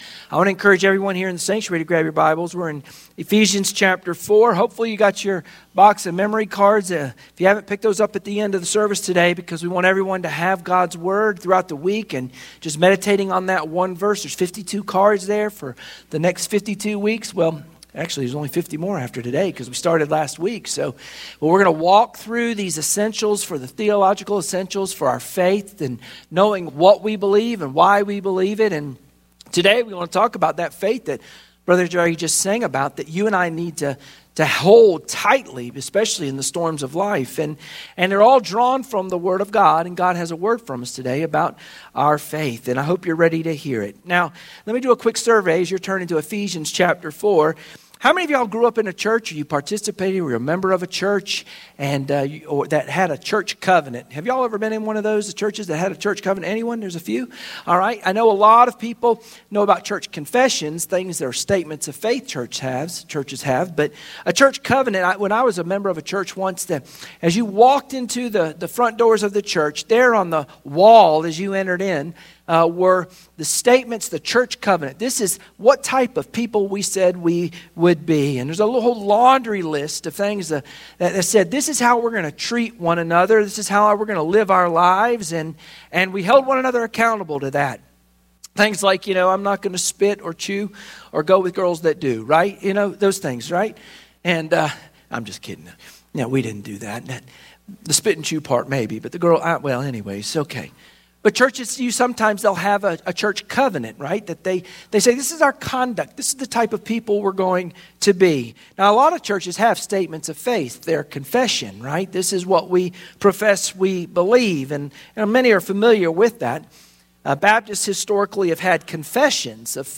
Sunday Morning Worship Passage: Ephesians 4:1-6 Service Type: Sunday Morning Worship Share this